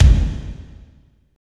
36.06 KICK.wav